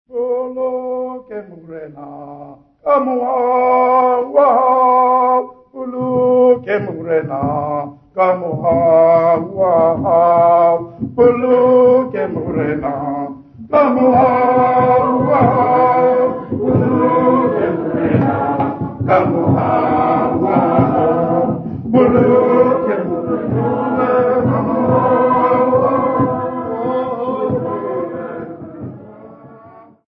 Music workshop participants
Folk music
Sacred music
Field recordings
Africa South Africa De Wildt sa
Church choral hymn with singing and clapping accompanied by drumming and a tambourine. Setswana church music composer's workshop.
96000Hz 24Bit Stereo